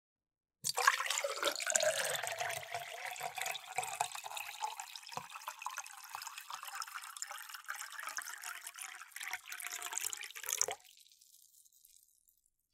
pouring_milk.ogg